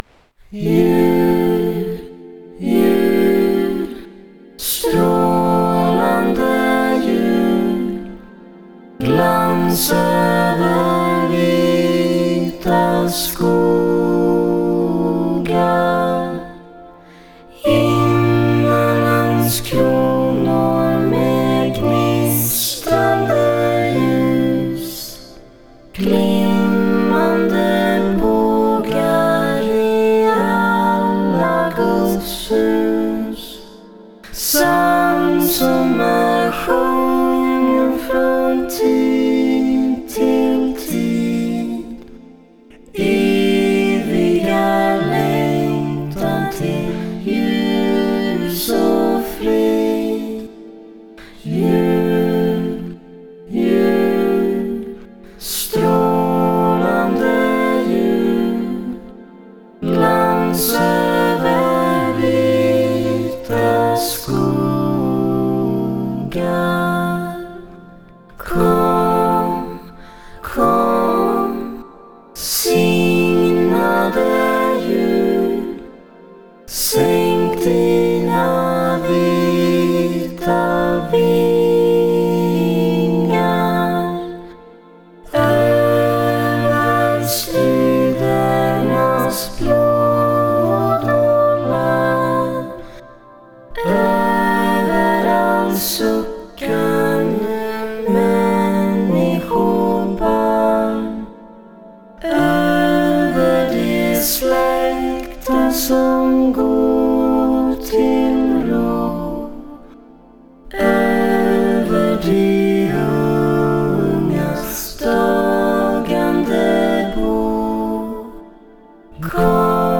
Suonano un pop elettronico molto etereo, intriso di R'n'B e sensualità, e qui potete sentire il loro ultimo EP Finding Out, che loro descrivono così: "the EP has a lot of dark themes and soundscapes.
Per questo Natale però hanno fatto qualcosa di diverso: hanno preso un vecchio canto tradizionale svedese e hanno fuso le loro voci dentro un suono scintillante che sembra quasi non avere corpo.